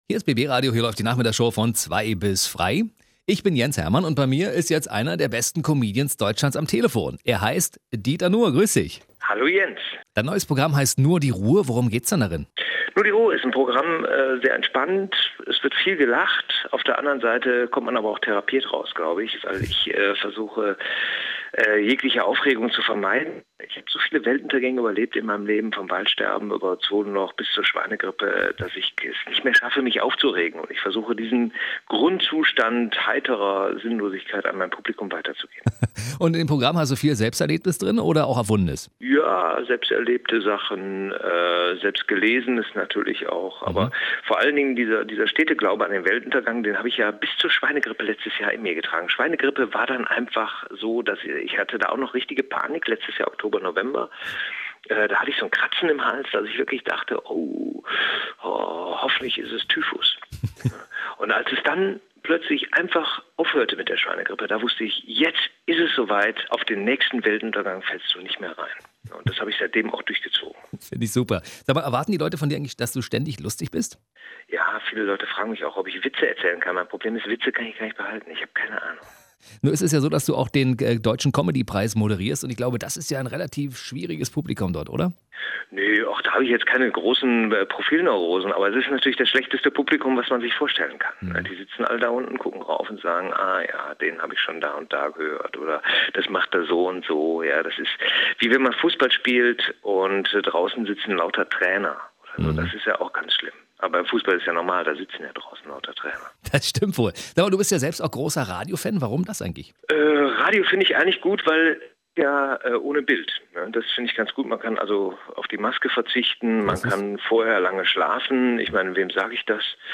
Interview Dieter Nuhr 2010
Interview_Dieter_Nuhr.mp3